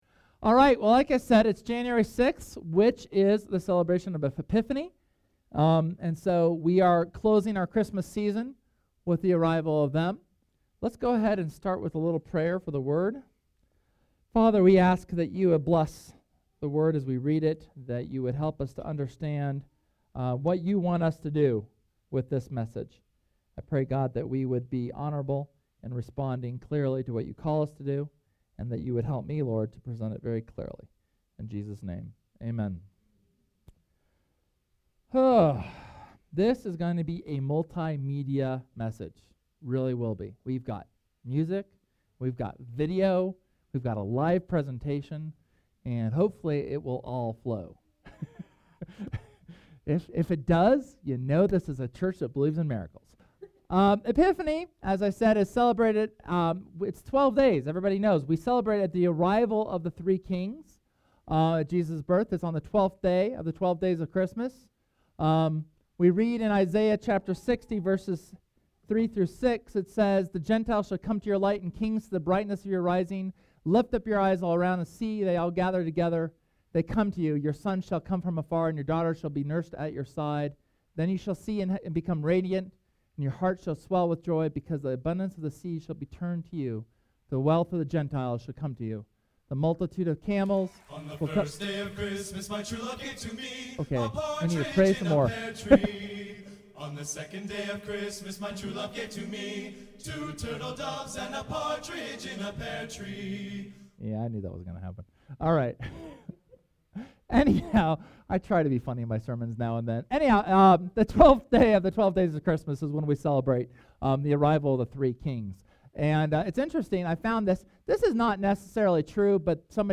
Sermon from Sunday, January 6th on the celebration of Epiphany.